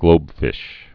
(glōbfĭsh)